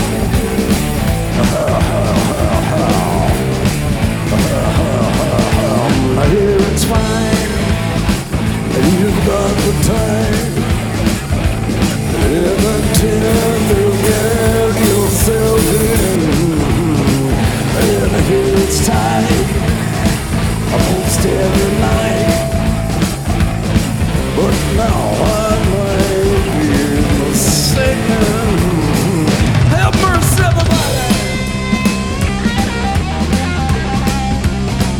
Жанр: Поп / Рок / Пост-хардкор / Хард-рок